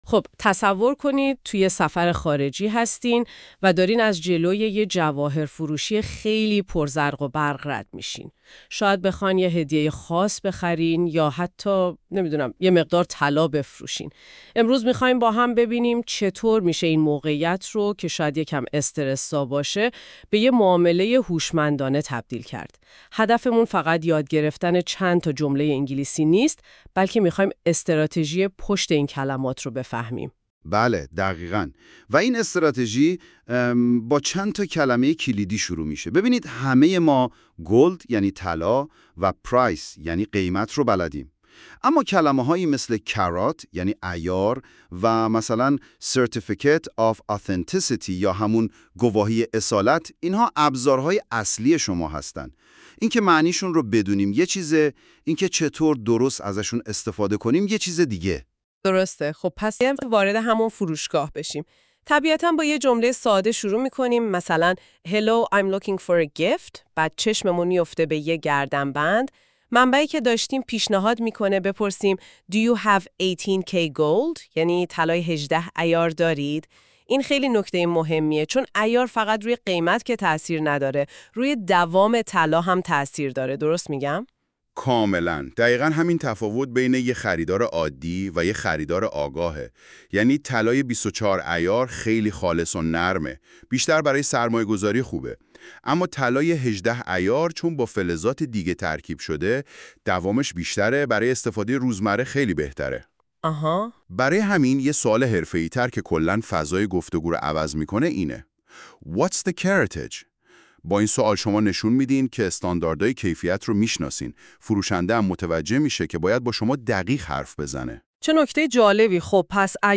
english-conversation-in-a-gold-shop.mp3